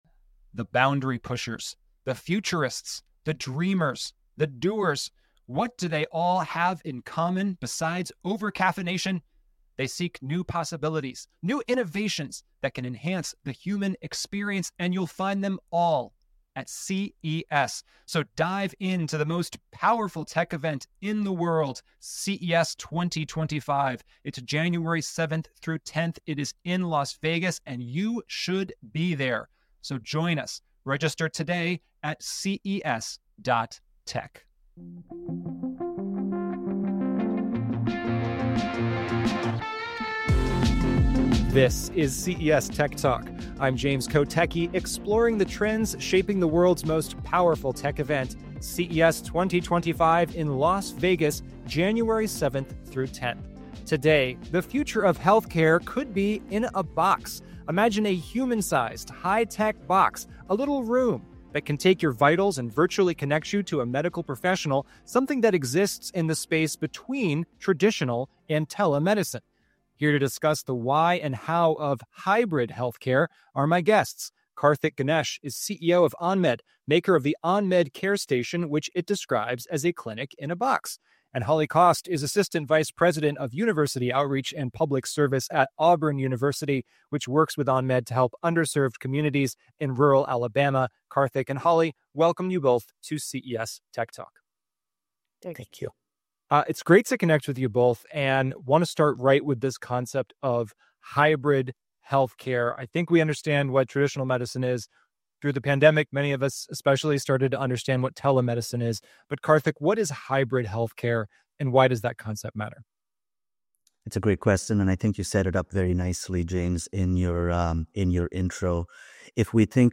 in the CES C Space Studio to explore the unique offerings Tubi provides to consumers, including the world’s largest ad-supported, free video library.